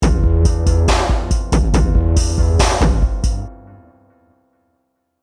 Download Dj Sound sound effect for free.
Dj Sound